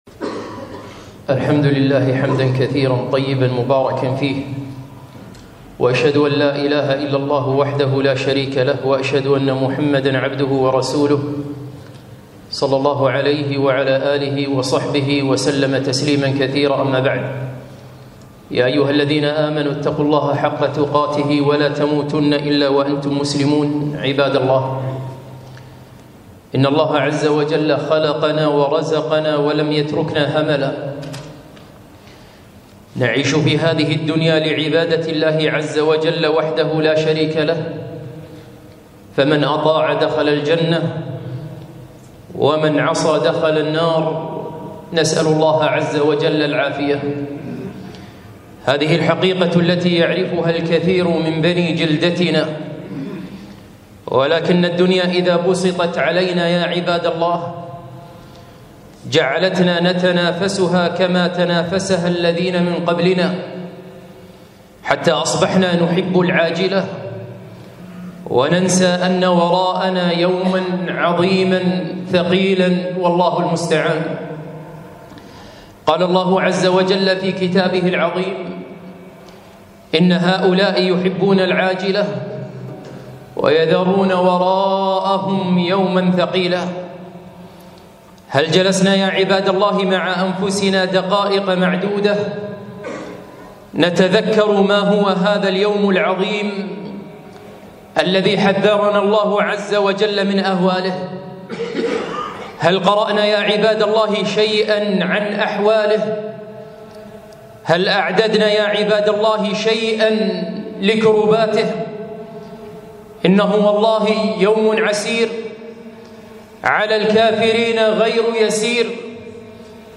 خطبة - يوم القيامة وما أدراكم ما يوم القيامة